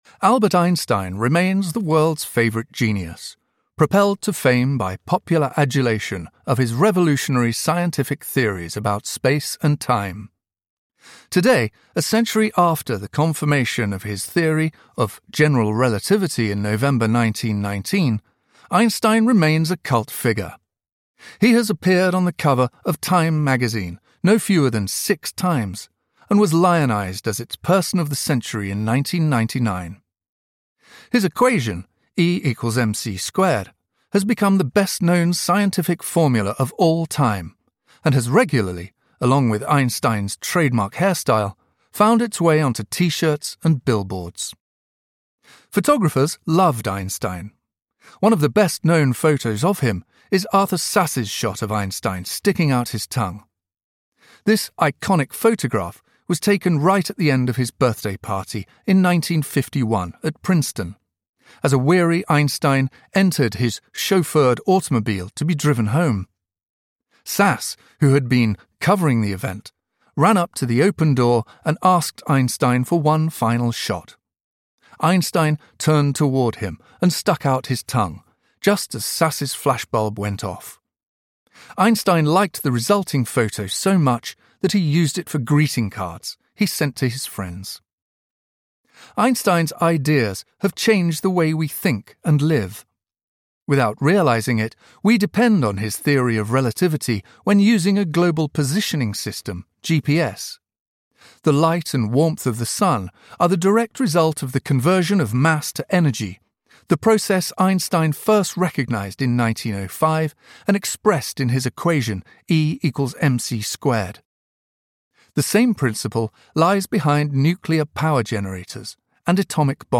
A Theory of Everything (That Matters) Audiobook
Narrator
5.0 Hrs. – Unabridged